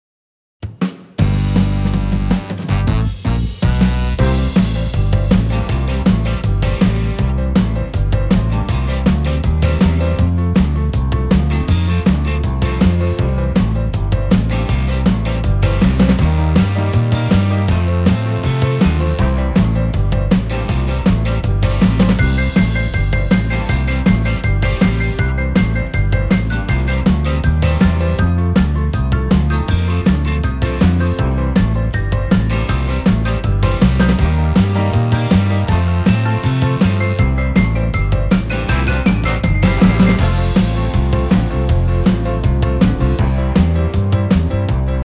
choir song